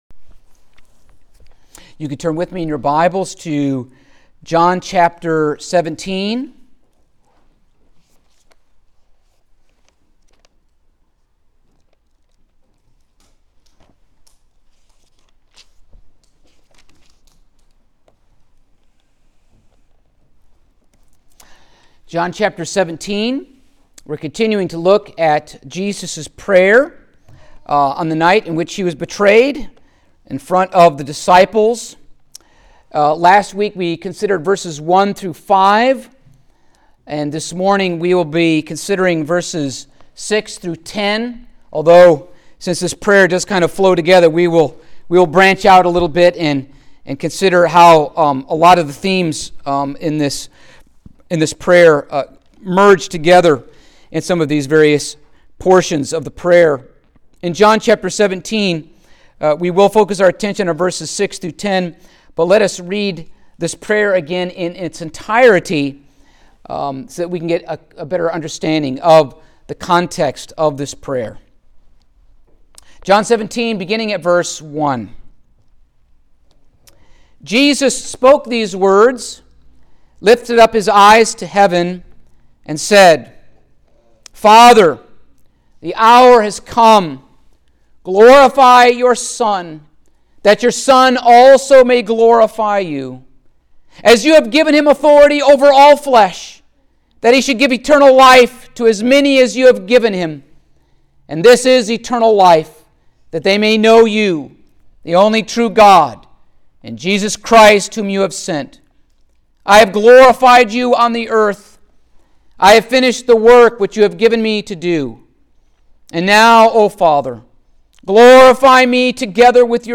Passage: John 17:6-10 Service Type: Sunday Morning